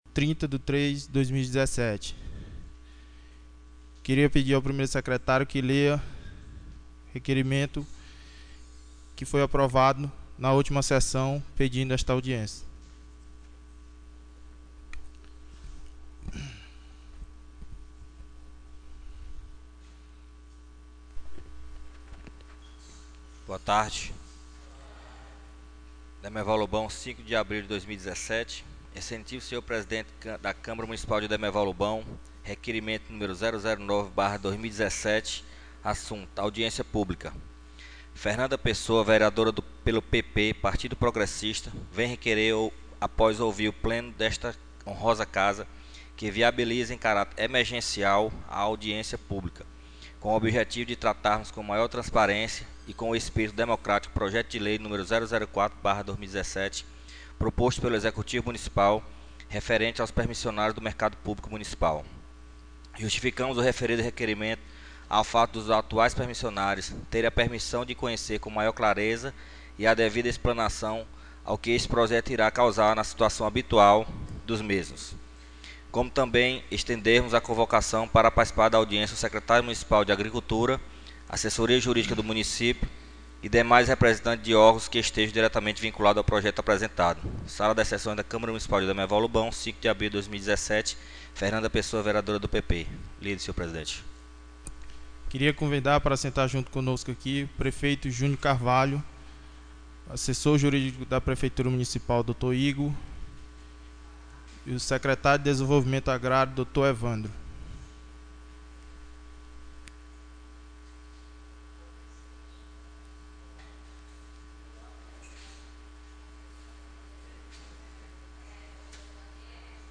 AUDIÊNCIA PÚBLICA 08/04/2017